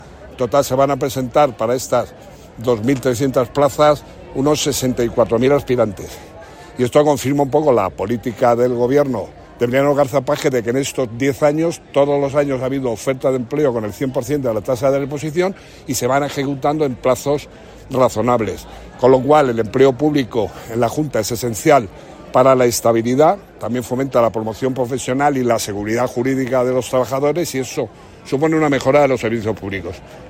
Consejería de Hacienda, Administraciones Públicas y Transformación Digital Sábado, 20 Septiembre 2025 - 11:00am Según ha señalado Narváez durante el inicio de los exámenes del proceso selectivo independiente para personas con discapacidad intelectual correspondientes a la Oferta de Empleo Público 2023 y 2024 en Administración General, en total se van a presentar para 2.300 plazas unos 64.000 aspirantes.